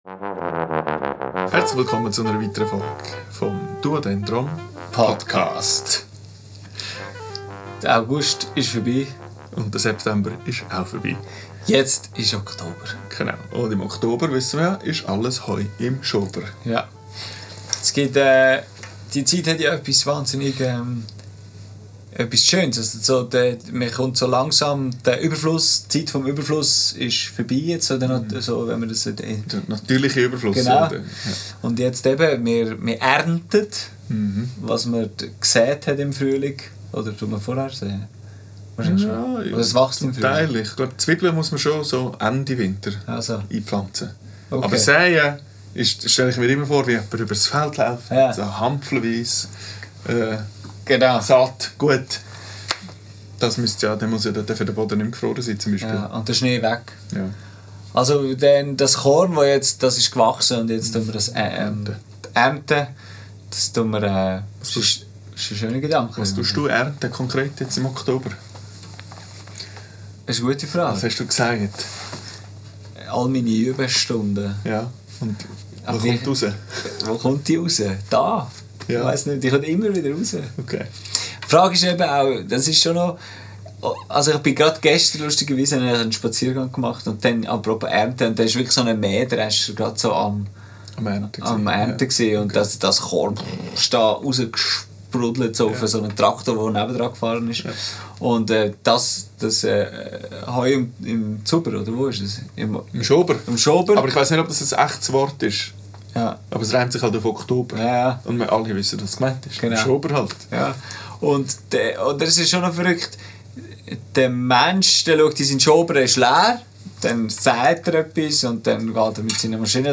Aufgenommen am 17.09.2025 im Atelier